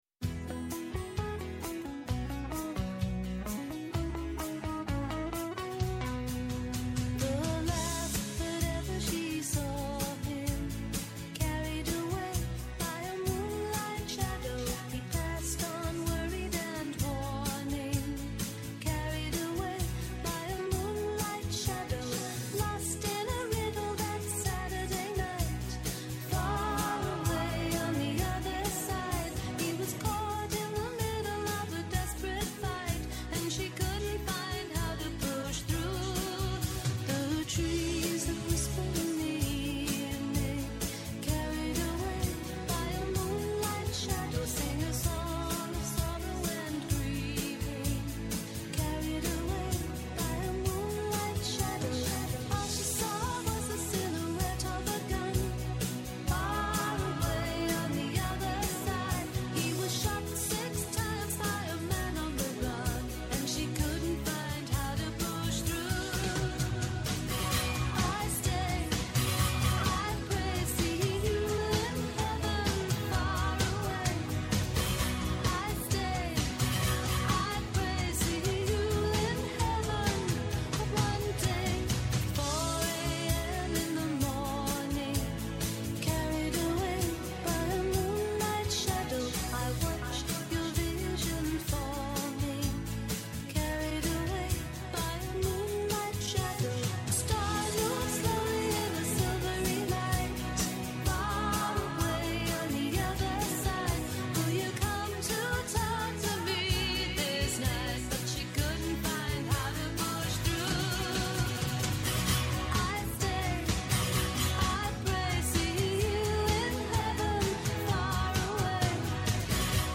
-O Χάρης Θεοχάρης, Yφυπουργός Οικονομικών
-O Θεόφιλος Ξανθόπουλος, βουλευτής ΣΥΡΙΖΑ